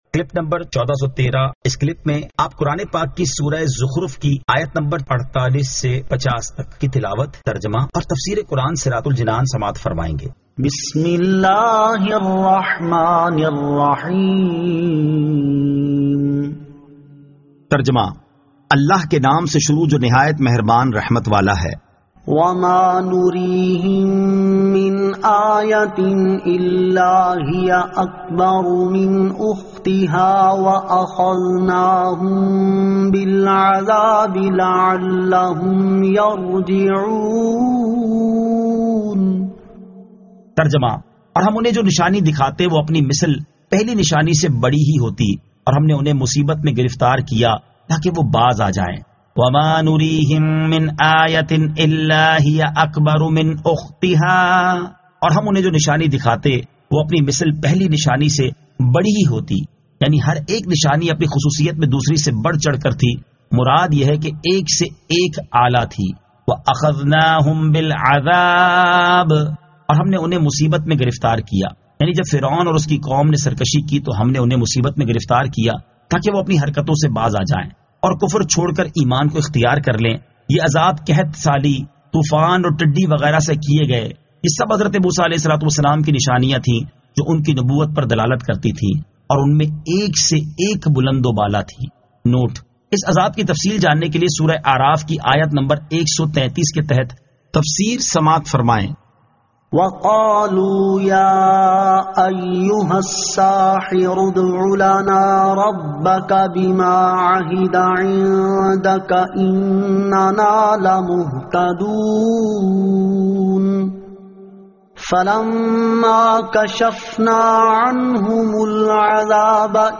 Surah Az-Zukhruf 48 To 50 Tilawat , Tarjama , Tafseer